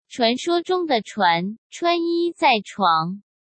Chuán shuō zhōng de chuán, chuān yī zài chuáng.
It is merely a tongue twister for practising your Chinese tone pronunciation and diction.